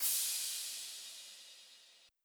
Crash [Goat Cym].wav